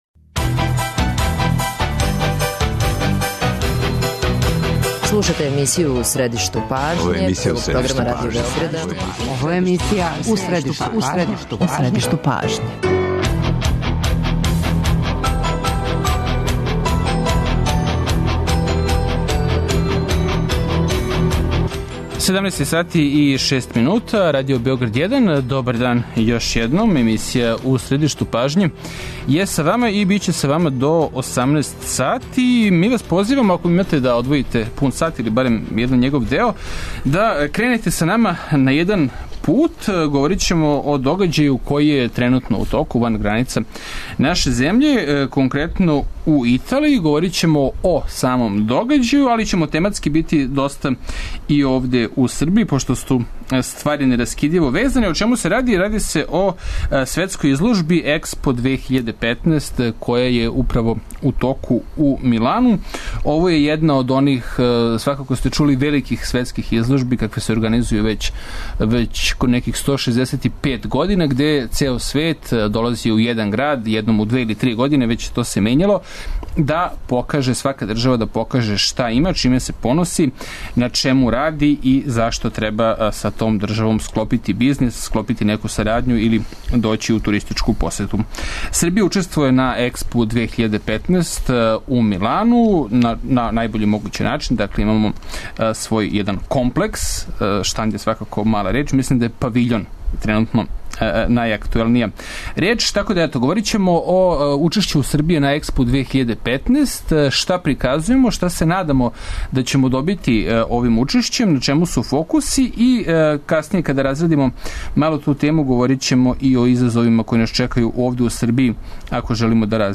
О наступу Србије на светској изложби и начинима за подстицање производње и извоза хране, као и о развоју етно туризма разговараћемо са помоћником министра трговине Жарком Малиновићем, а укључићемо и наше представнике у Милану.